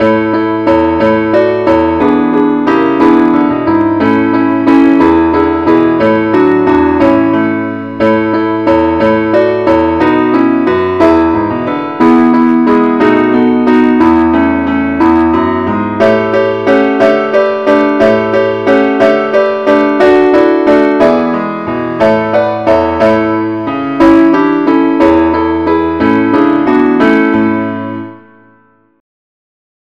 1. A MajorA Major [PDF][GIF] [AU]